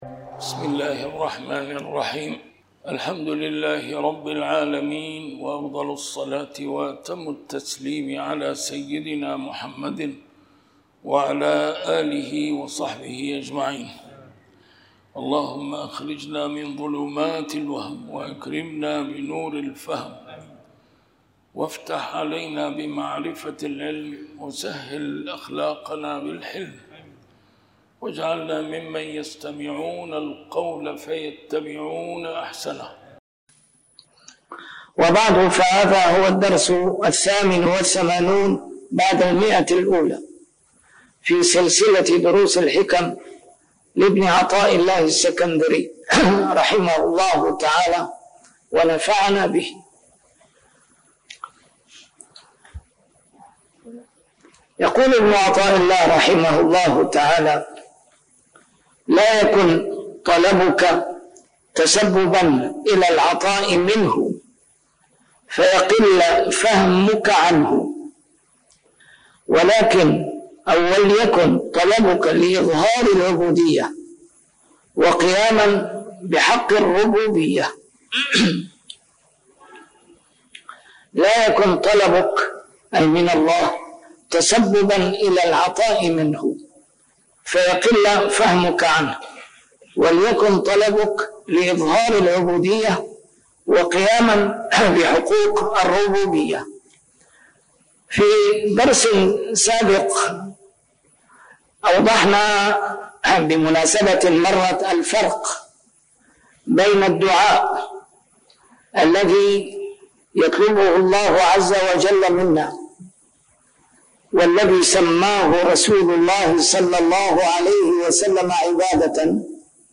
شرح الحكم العطائية - A MARTYR SCHOLAR: IMAM MUHAMMAD SAEED RAMADAN AL-BOUTI - الدروس العلمية - علم السلوك والتزكية - الدرس رقم 188 شرح الحكمة 166